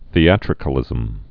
(thē-ătrĭ-kə-lĭzəm)